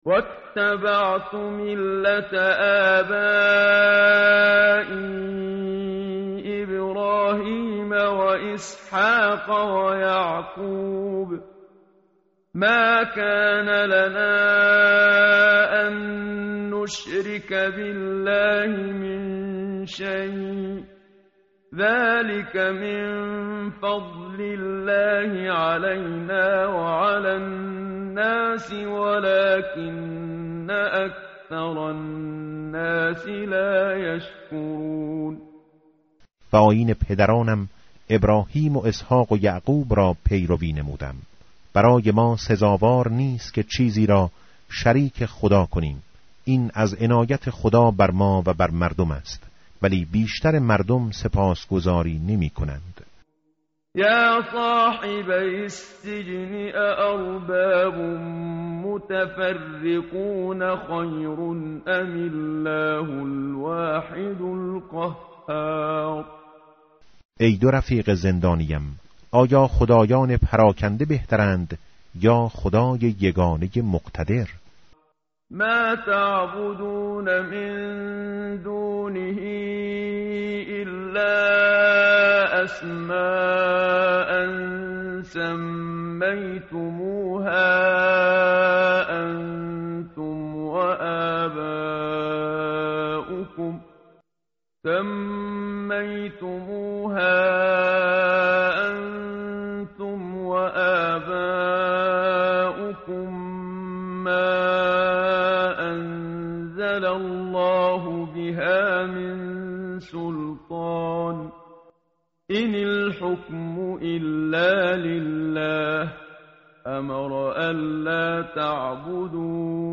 tartil_menshavi va tarjome_Page_240.mp3